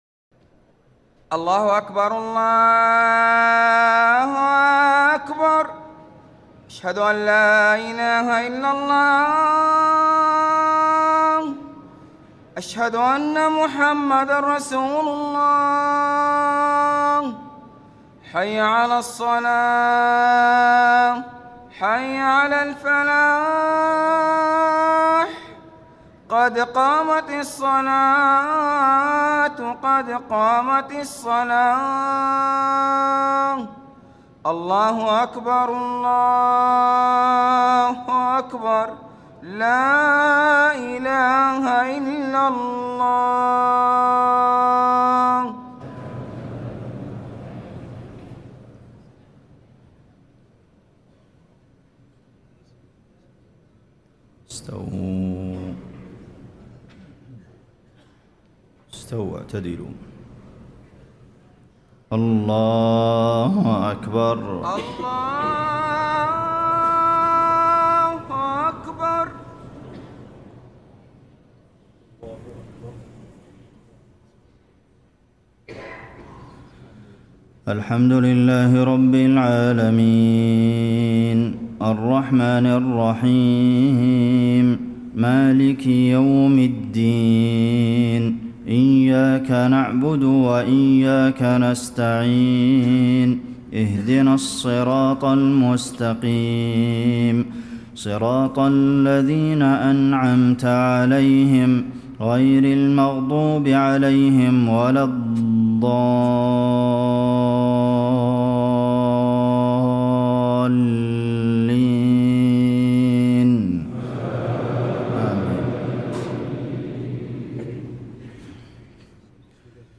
مغرب 15 شعبان ١٤٣٥ سورة القدر والاخلاص > 1435 🕌 > الفروض - تلاوات الحرمين